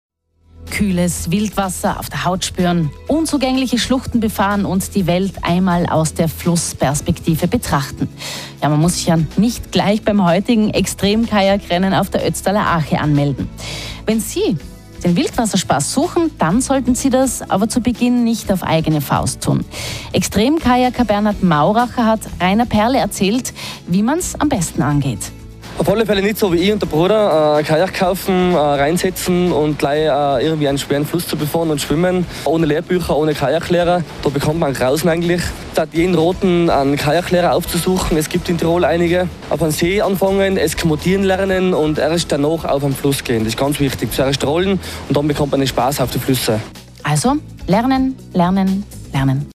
Radio Tirol Interviews